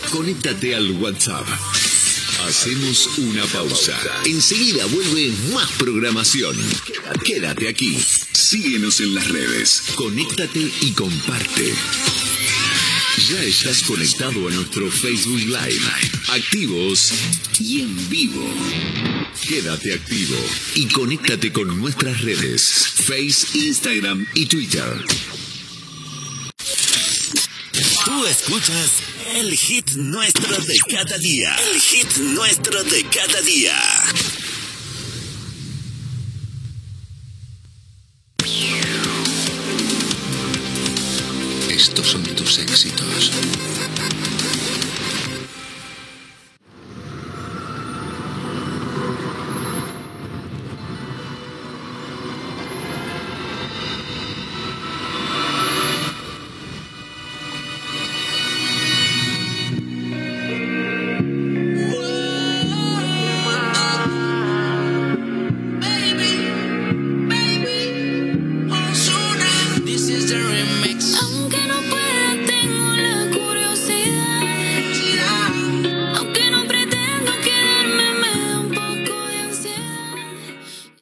Formes d'escoltar la ràdio, indicatiu del programa i tema musical
Musical